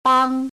bāng
bang1.mp3